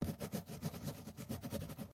pda_note.ogg